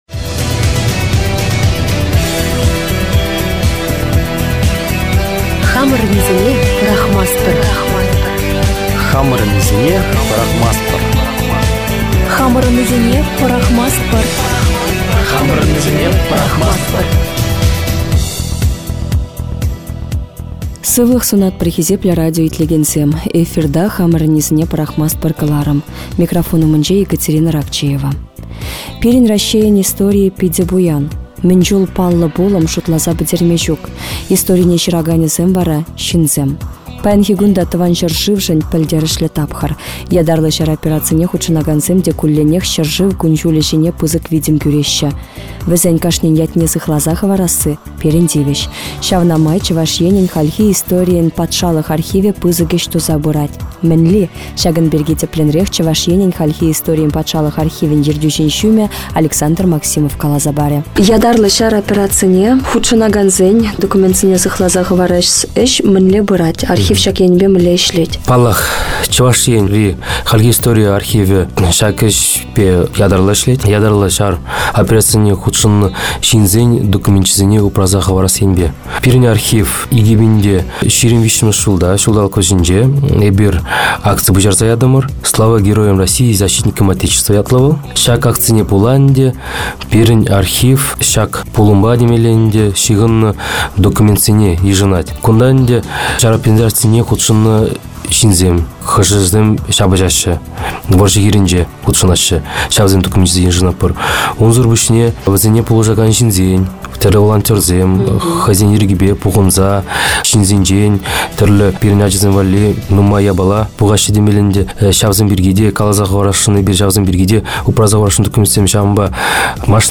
Хамăрăннисене пăрахмастпăр (радиопередача НТРК Чувашии от 10.03.2025)